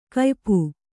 ♪ kaypu